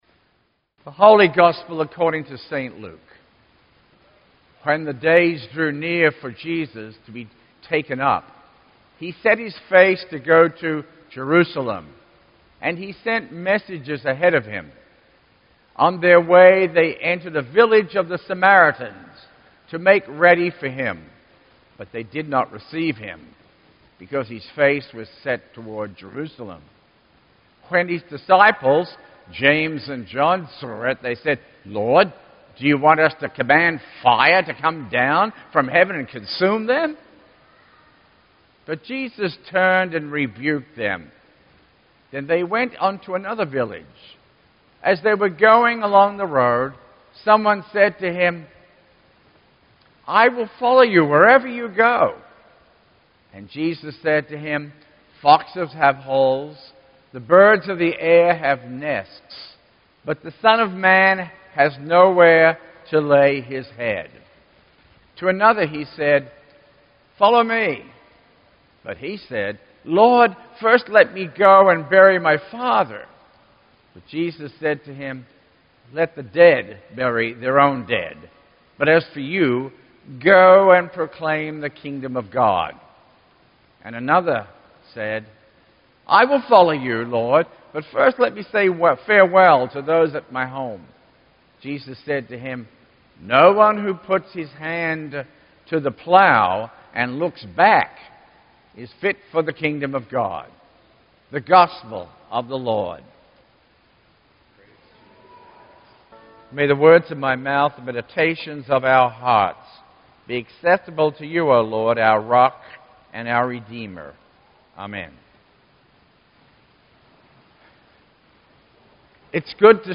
Sermon-6_26_16.mp3